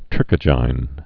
(trĭkə-jīn, -gīn)